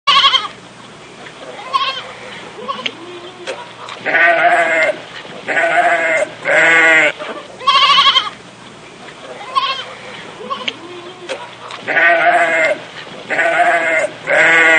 Catégorie Effets Sonores